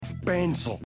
PLAY pencil sound effect
pencil.mp3